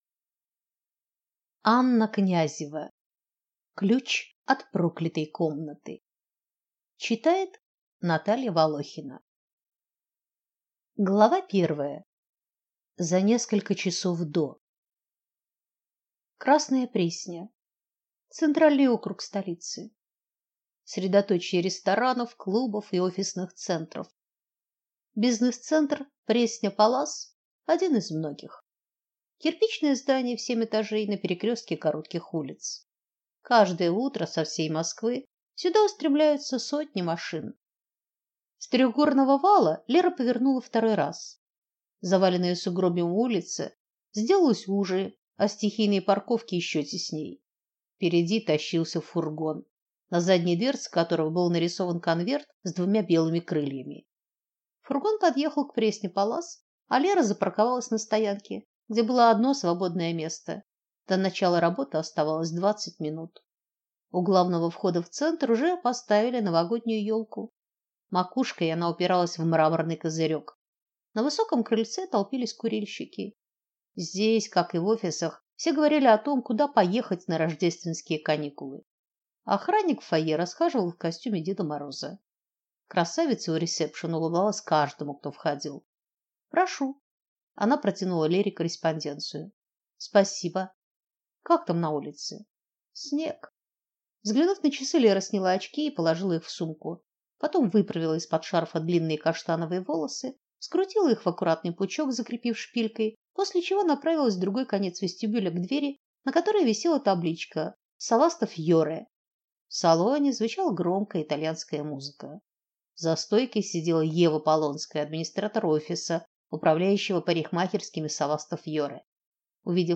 Аудиокнига Ключ от проклятой комнаты | Библиотека аудиокниг